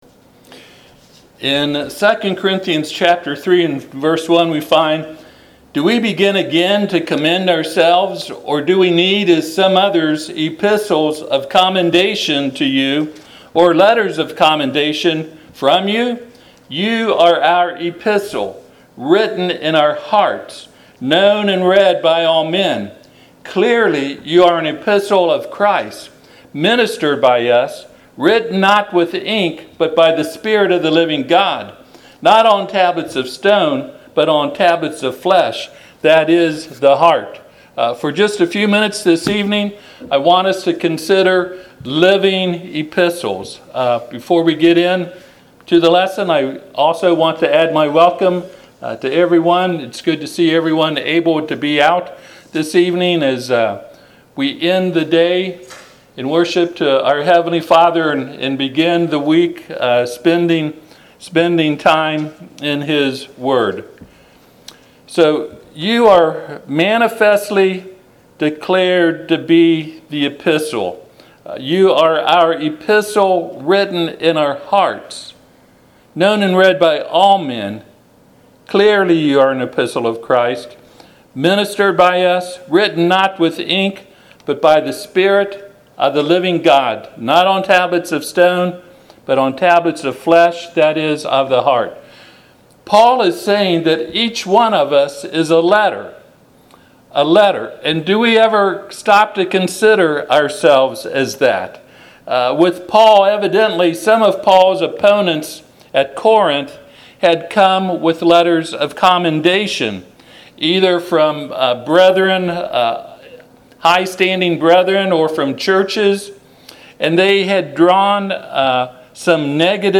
Passage: 2 Corinthians 3:1-3 Service Type: Sunday PM 2Corinthians 3:1 Do we begin again to commend ourselves?